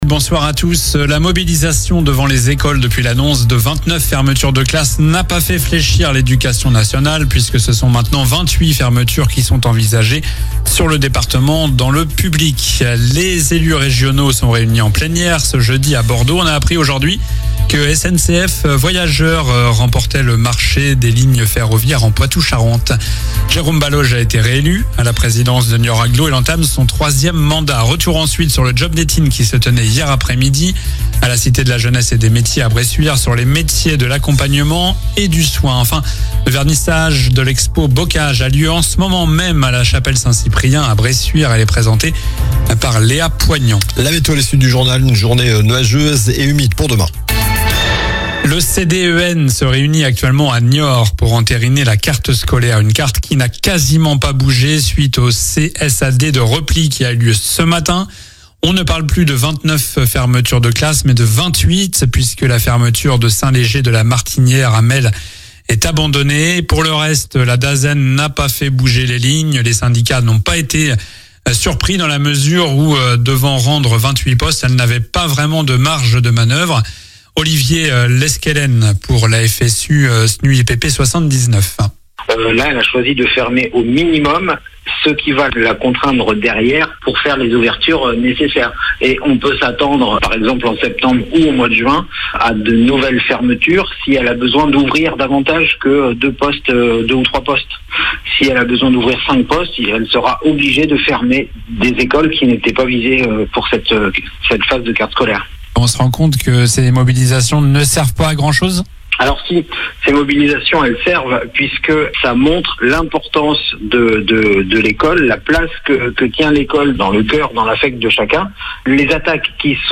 Journal du jeudi 2 avril (soir)